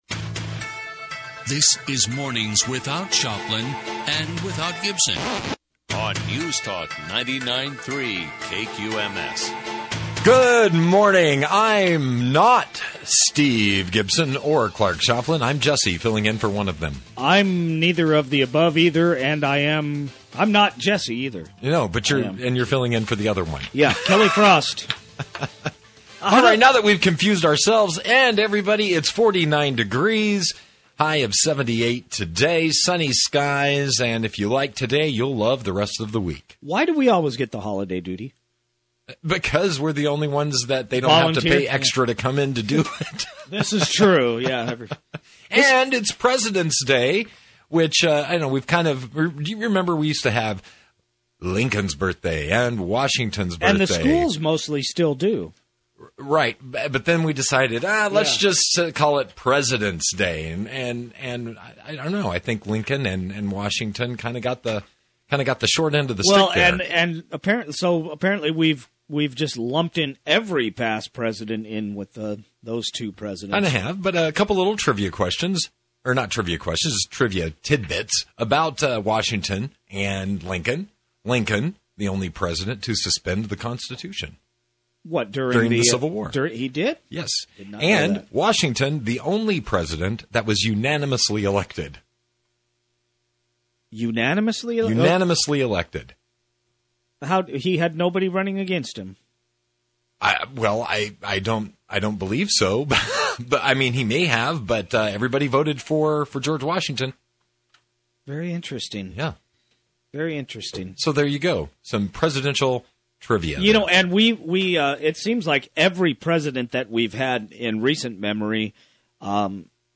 Financial Fridays radio show on KLAV 1230 AM in Las Vegas, NV.